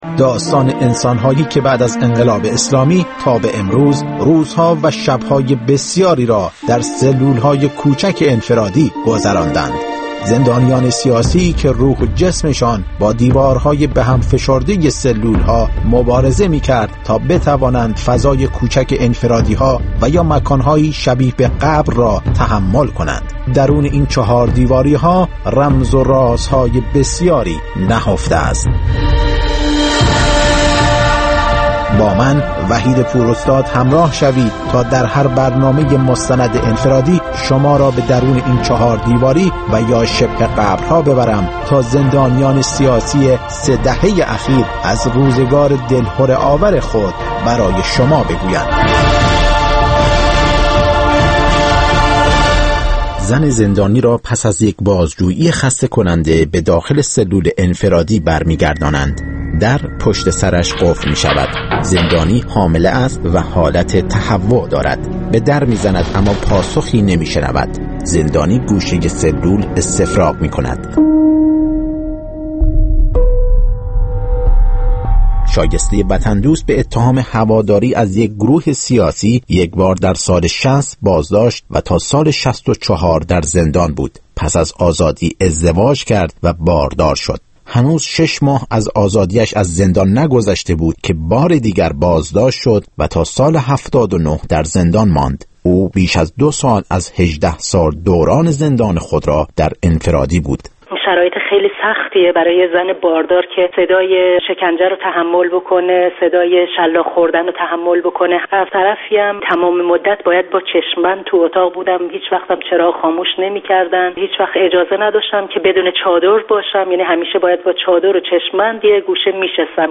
مستند رادیویی «انفرادی» هر هفته سه‌شنبه‌ها در ساعت ۱۸ به وقت ایران پخش می‌شود و روزهای پنجپنج‌شنبه ساعت ۱۴، روزهای جمعه ساعت ۹ صبح و یکشنبه‌ها ساعت ۲۳ به وقت ایران، تکرار می‌شود.